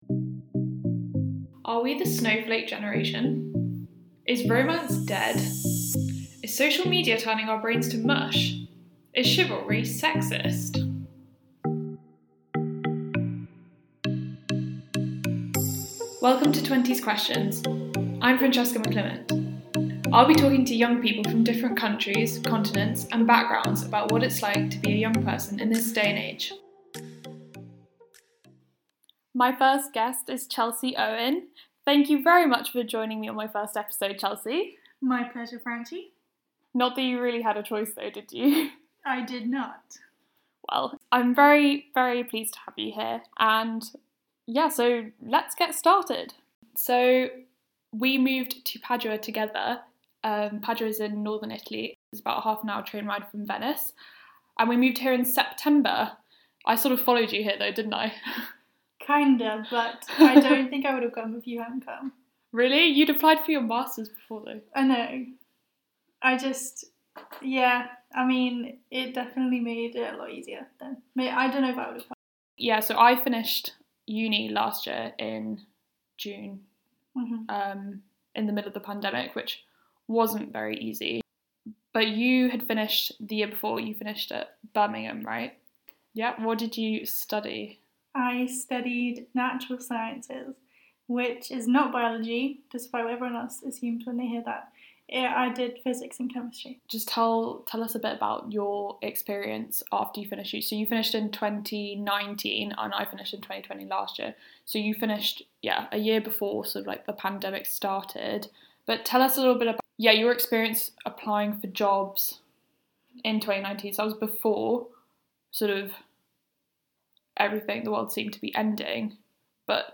In between friendly banter and jokey asides, the conversation spans social problems such as youth unemployment, gender stereotypes and the role of social media.